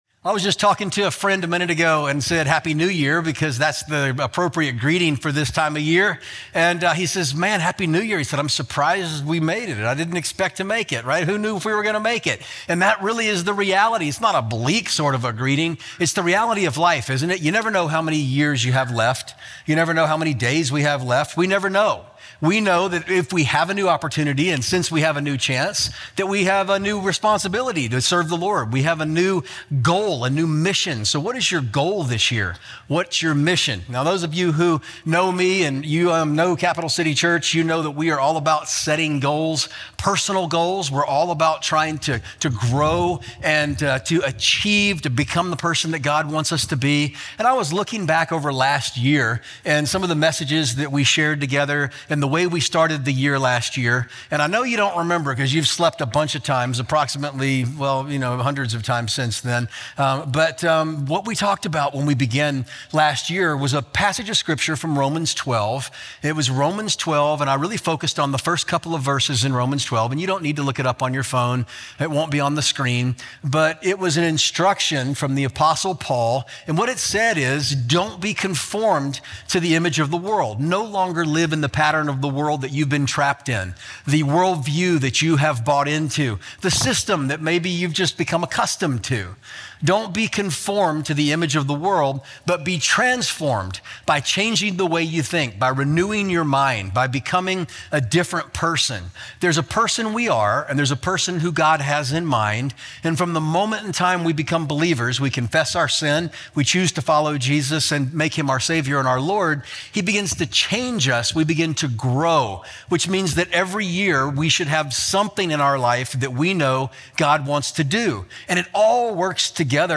Capitol City Church Podcast (Sermon Audio) (Sermon Audio)